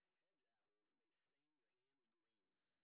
sp08_street_snr30.wav